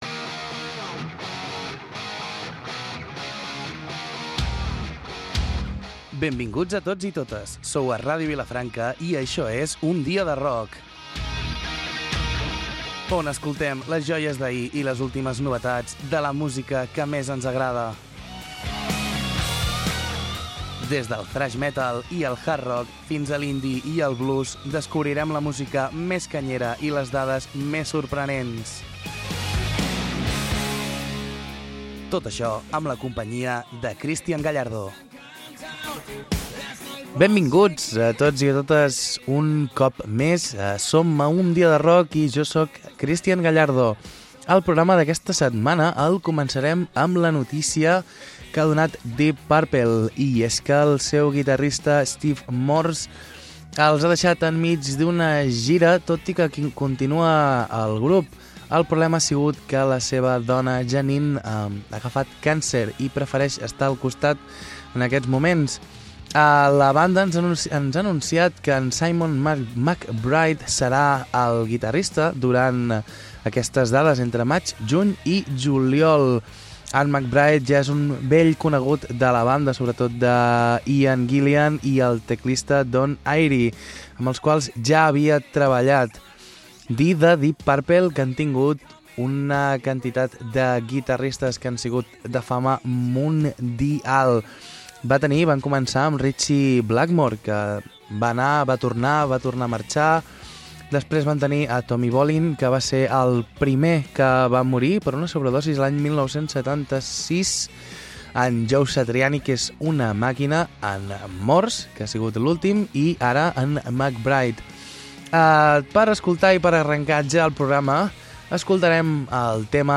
Programa de rock